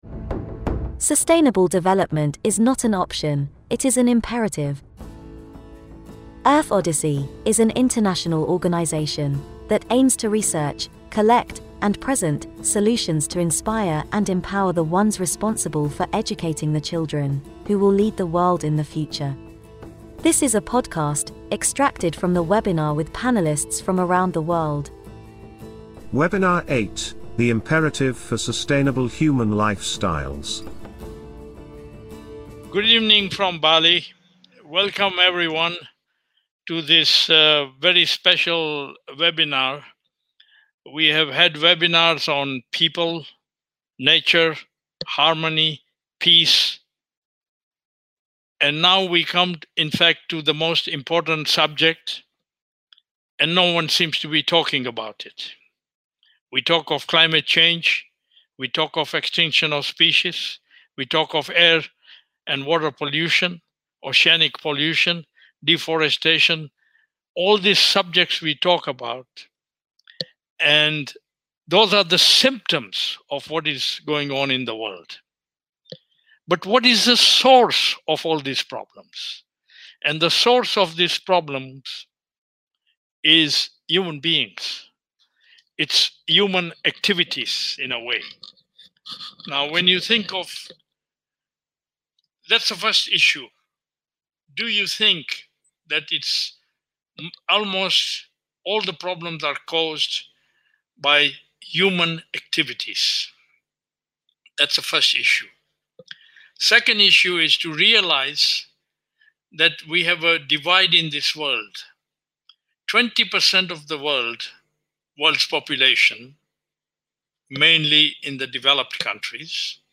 WEBINAR8.mp3